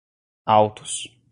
Pronúnciase como (IPA)
/ˈaw.tus/